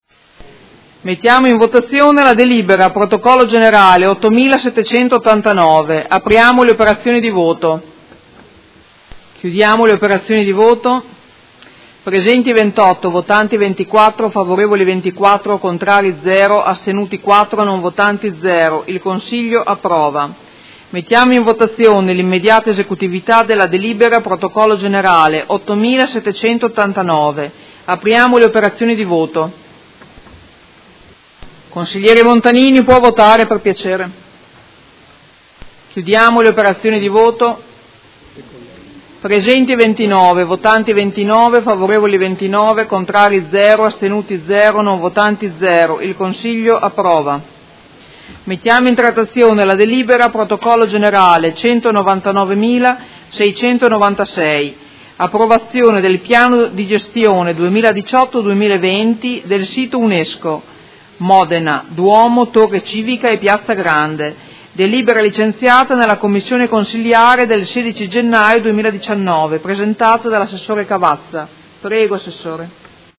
Presidente — Sito Audio Consiglio Comunale
Seduta del 24/01/2019.